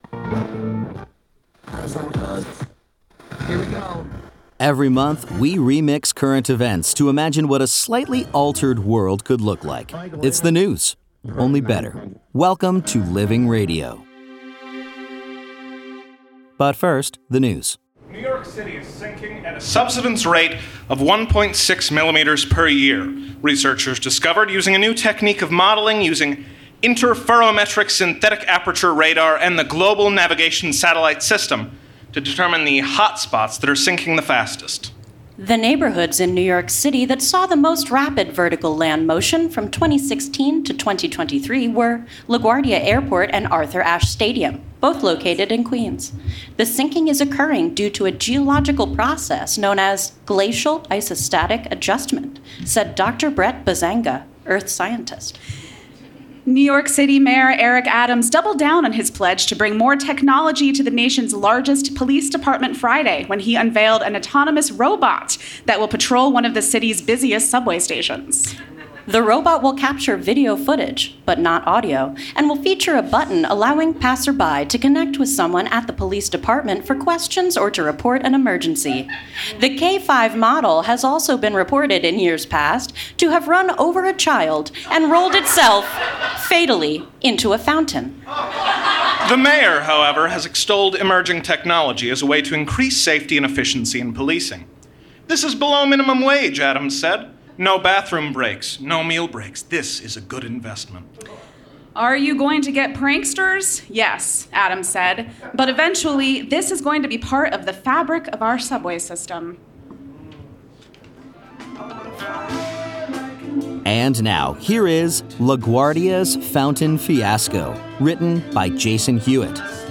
Performed and recorded live October 4, 2023, at The Kraine Theater, New York City.